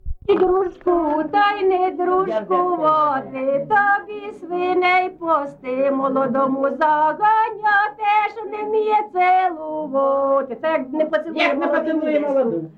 ЖанрВесільні
Місце записус. Шарівка, Валківський район, Харківська обл., Україна, Слобожанщина